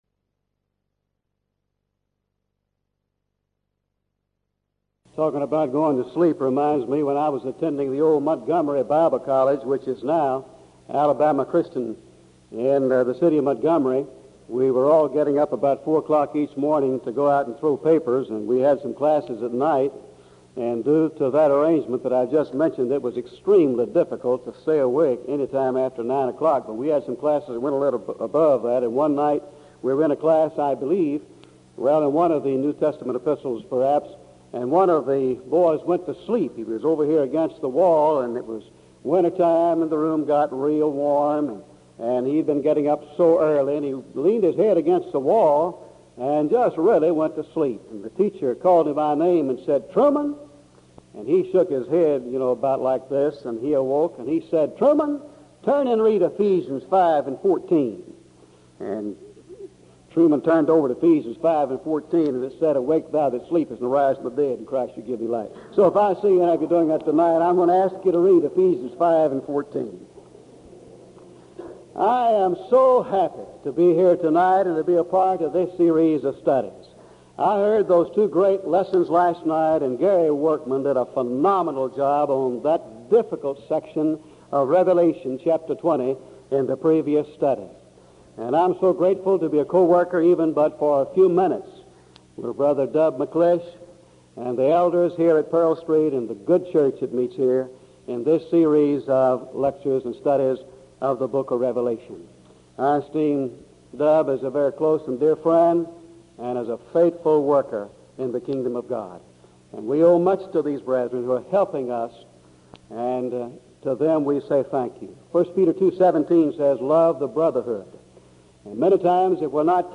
Event: 1984 Denton Lectures Theme/Title: Studies in the Book of Revelation